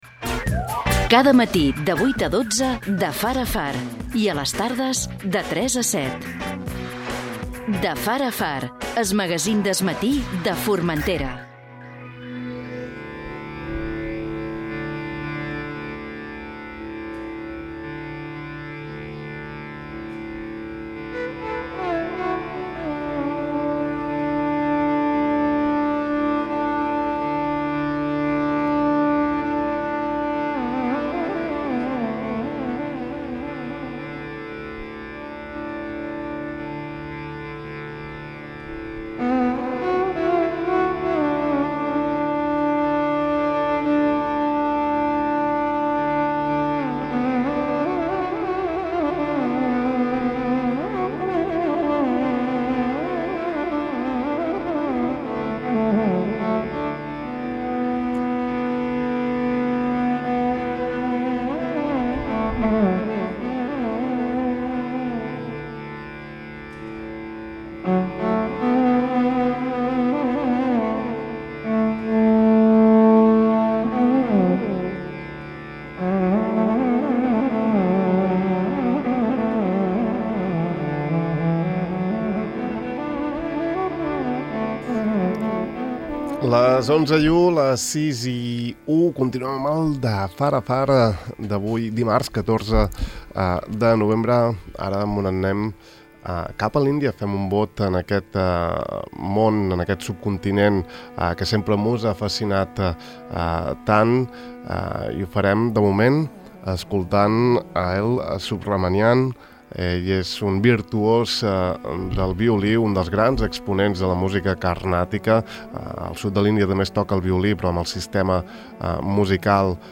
A l’estudi 1 de Ràdio Illa Podeu escoltar la conversa sencera clicant aquí